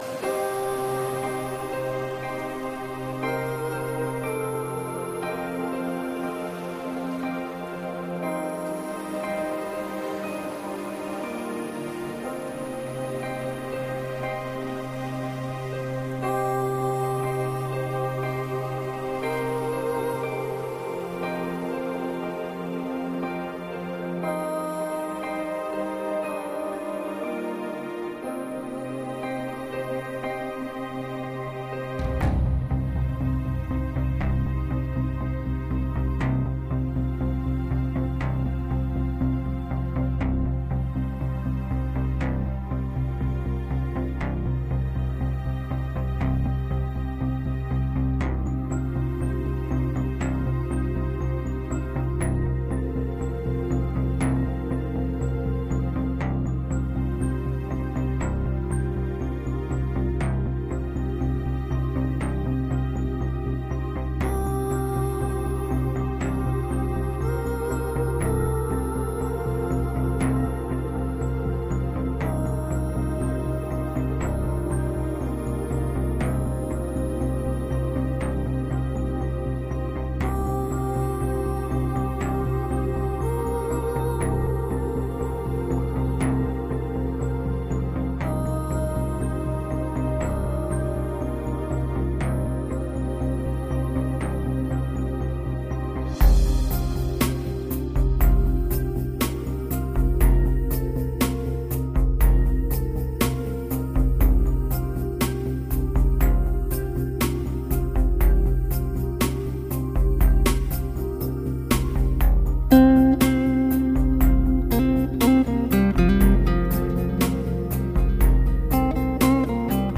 该唱片融入了诸多超凡脱俗的声音、并加入哥特式合唱团、吉他、鼓声等等形成了一个完整的多乐器音乐合集。